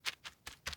Added Walking and Sprinting SFX
Sprint.wav